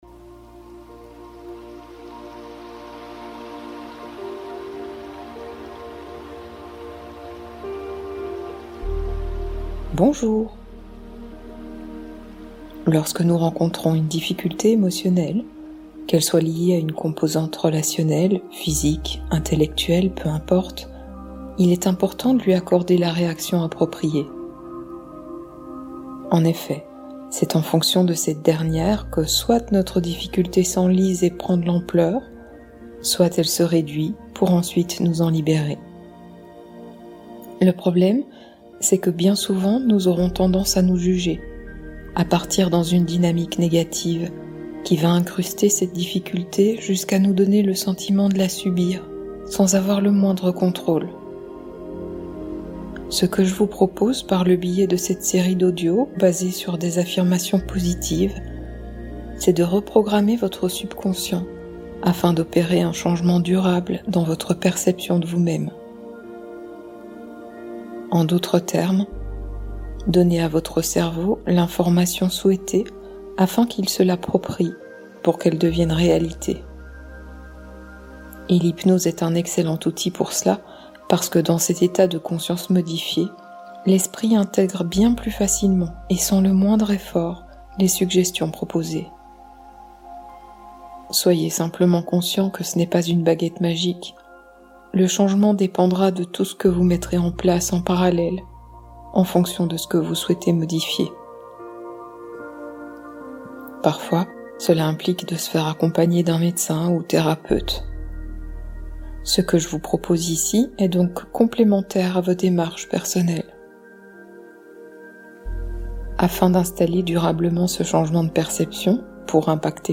Méditation Guidée